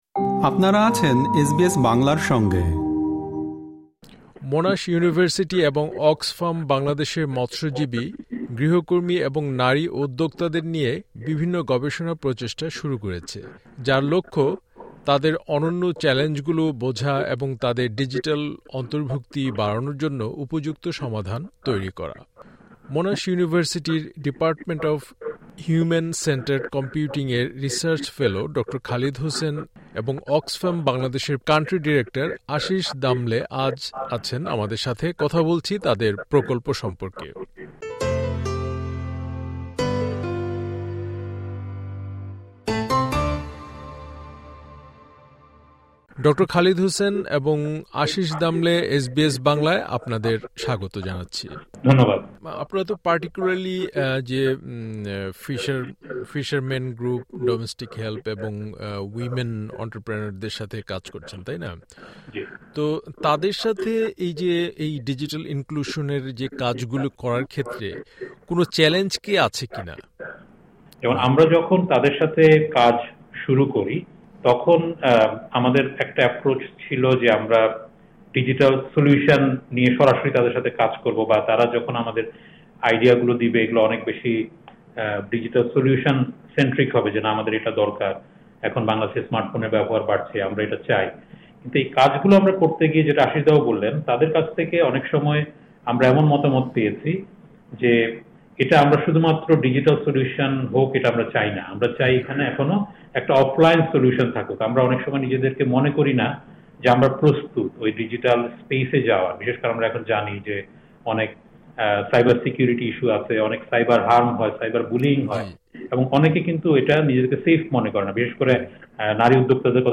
মোনাশ ইউনিভার্সিটি এবং অক্সফাম বাংলাদেশের মৎস্যজীবী, গৃহকর্মী এবং নারী উদ্যোক্তাদের নিয়ে বিভিন্ন গবেষণা প্রচেষ্টা শুরু করেছে, যার লক্ষ্য তাদের নির্দিষ্ট চ্যালেঞ্জগুলি বোঝা এবং ডিজিটাল অন্তর্ভুক্তি বাড়ানোর জন্য উপযুক্ত সমাধান তৈরি করা। এখানে প্রকাশিত হলো সাক্ষাৎকারের দ্বিতীয় অংশ।